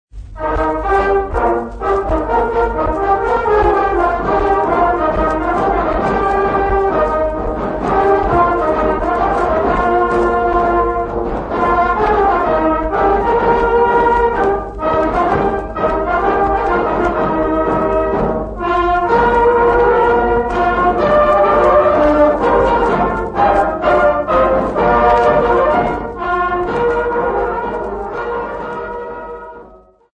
Folk Music
Field recordings
sound recording-musical
Indigenous music